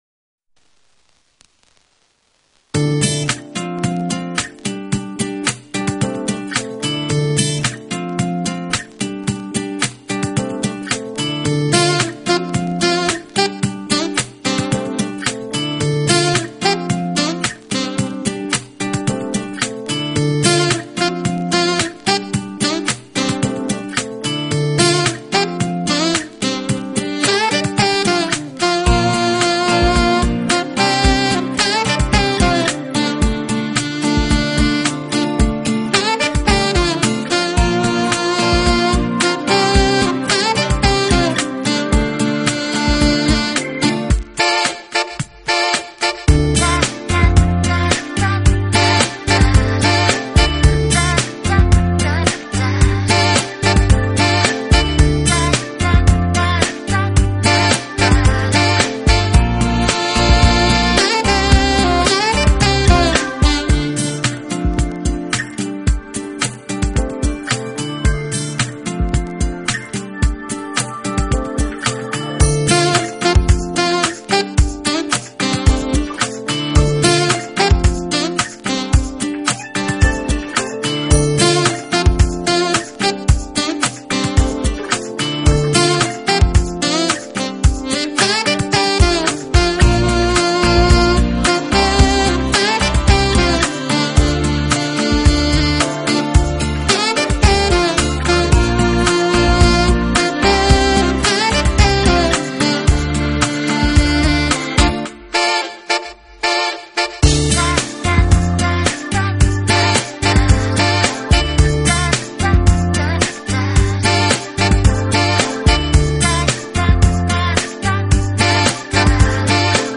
【爵士萨克斯】